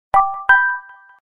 Charming Bell Sound Effect Free Download
Charming Bell